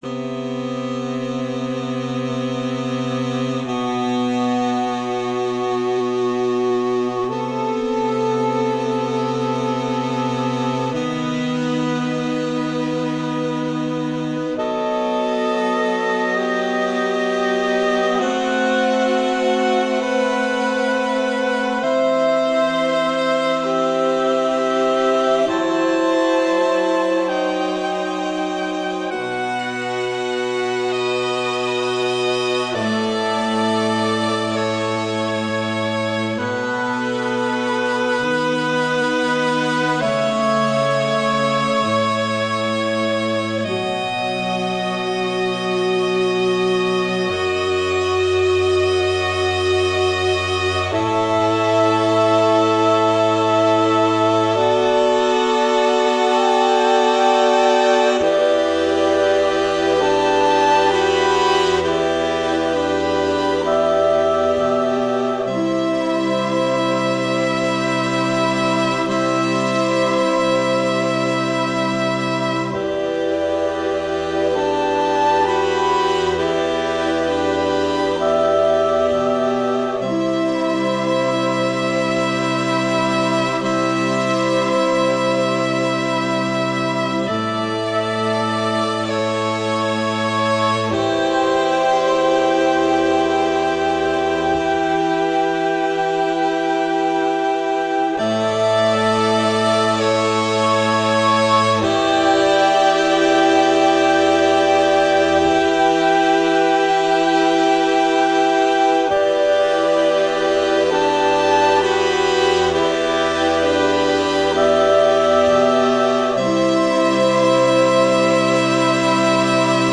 For Saxophones
The-8th-Sea-Sax-5tet.mp3